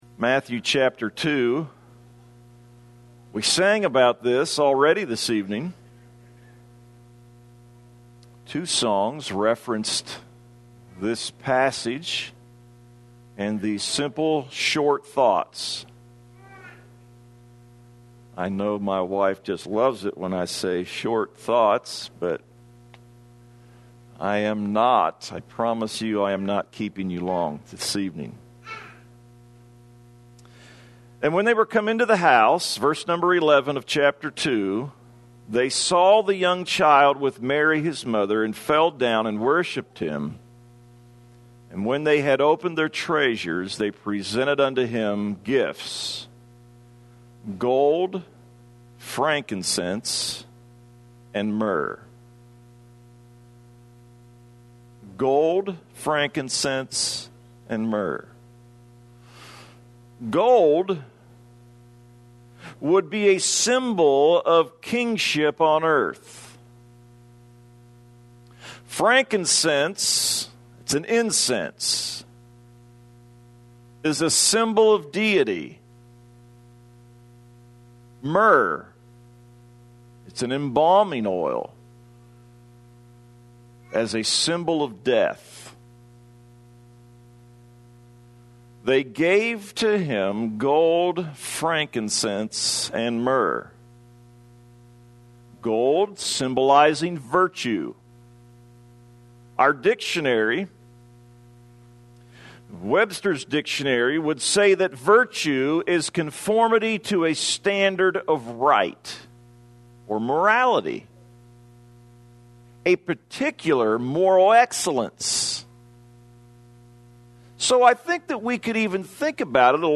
Save Audio A short sermon